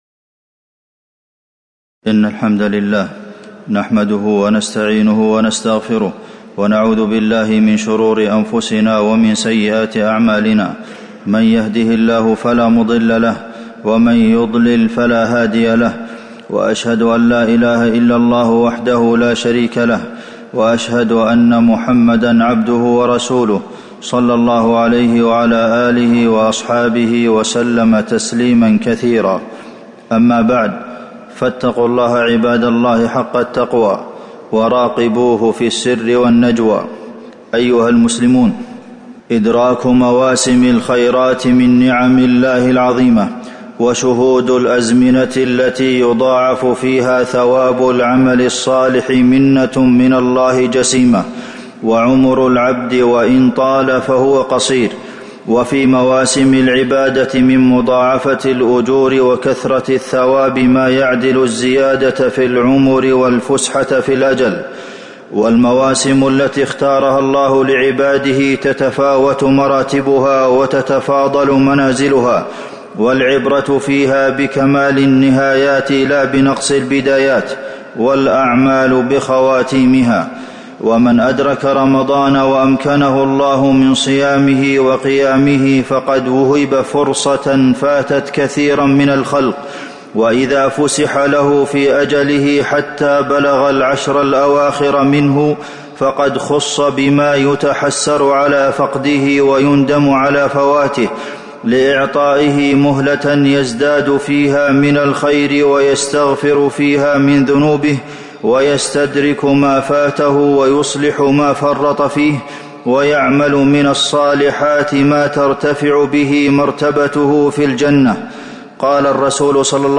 تاريخ النشر ٢١ رمضان ١٤٤٣ هـ المكان: المسجد النبوي الشيخ: فضيلة الشيخ د. عبدالمحسن بن محمد القاسم فضيلة الشيخ د. عبدالمحسن بن محمد القاسم نعمة إدراك العشر الأواخر من رمضان